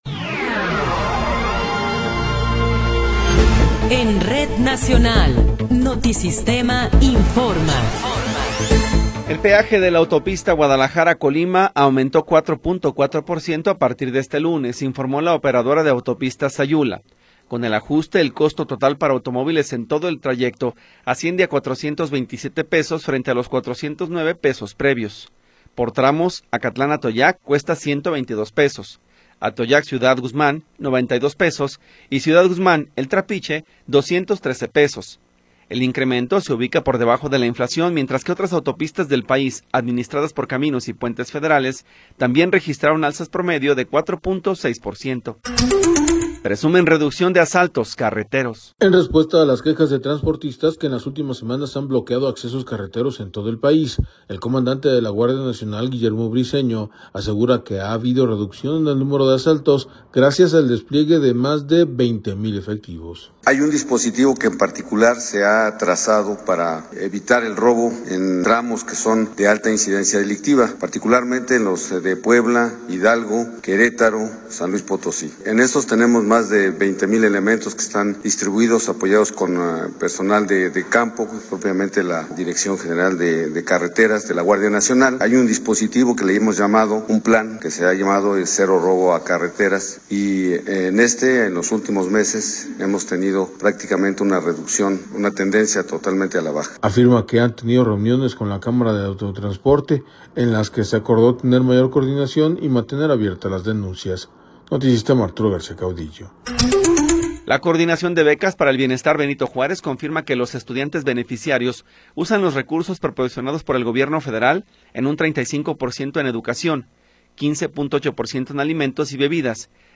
Noticiero 15 hrs. – 18 de Abril de 2026